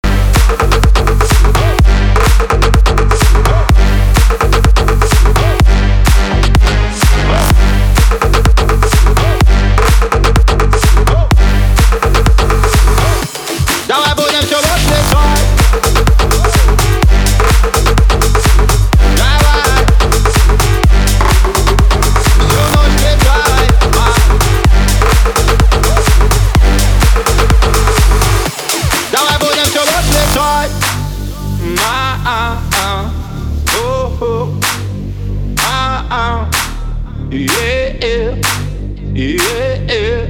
• Качество: 320, Stereo
громкие
мощные
Club House
Энергичный ремикс